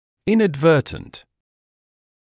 [ˌɪnəd’vɜːtənt][ˌинэд’вёːтэнт]непреднамеренный, случайный, неумышленный, нечаянный